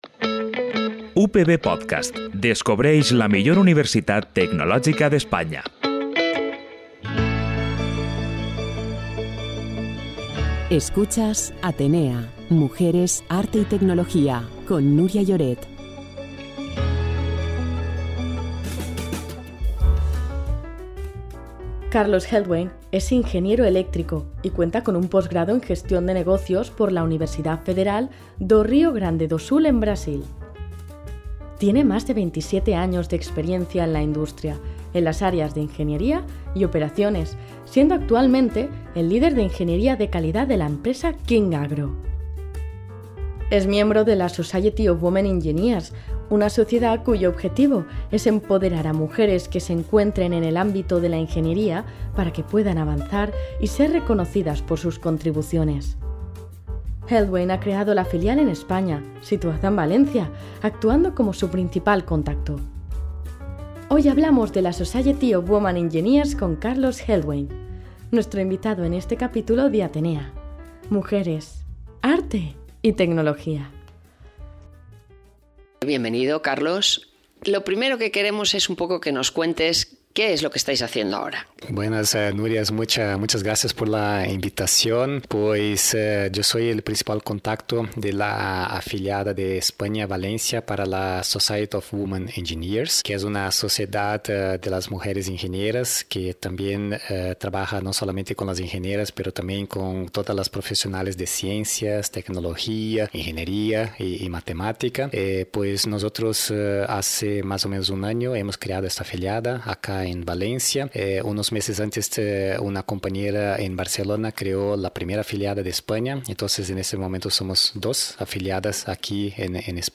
Cada quinze dies gaudirem d’un nou episodi en el qual descobrirem, amb una entrevista en profunditat, les nostres convidades, relacionades amb la tecnologia, el metavers, els criptovalors no fungibles (NFT)… i l’art.